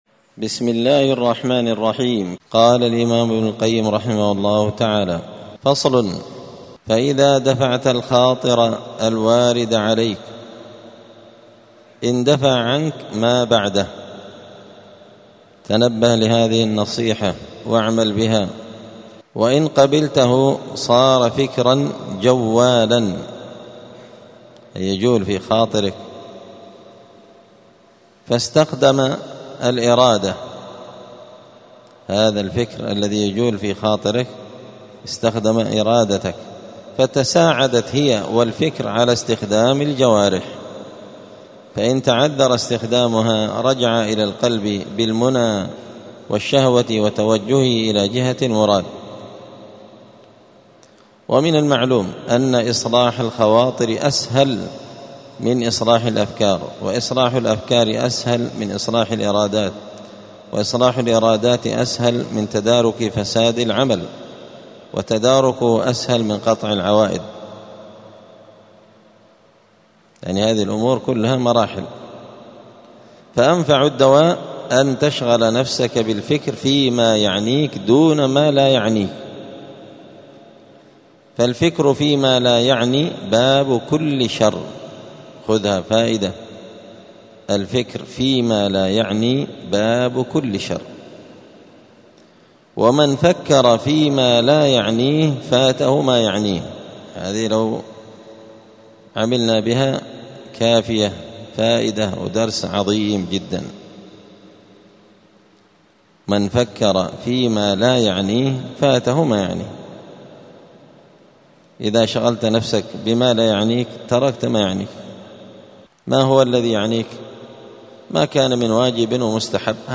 الأحد 13 ربيع الثاني 1447 هــــ | الدروس، دروس الآداب، كتاب الفوائد للإمام ابن القيم رحمه الله | شارك بتعليقك | 7 المشاهدات